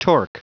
Prononciation du mot torque en anglais (fichier audio)
Prononciation du mot : torque